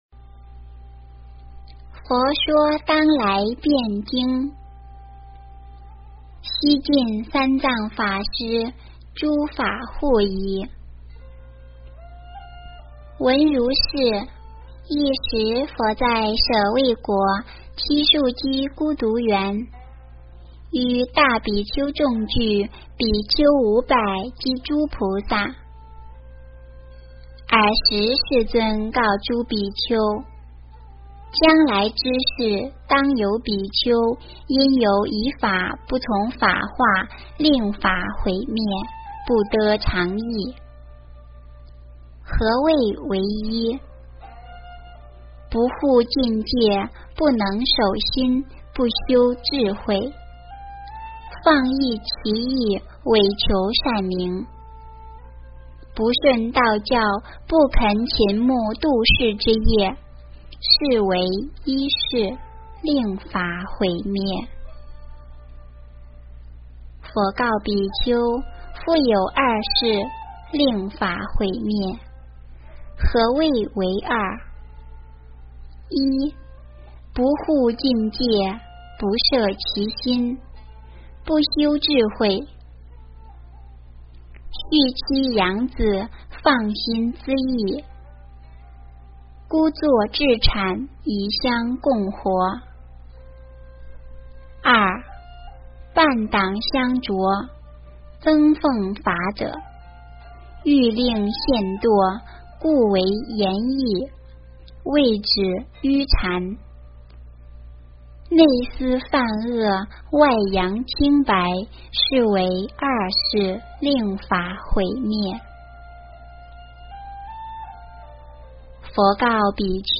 诵经
佛音 诵经 佛教音乐 返回列表 上一篇： 佛说阿弥陀三耶三佛萨楼佛檀过度人道经C 下一篇： 发菩提心经论卷下 相关文章 大悲咒-藏音--藏地密咒 大悲咒-藏音--藏地密咒...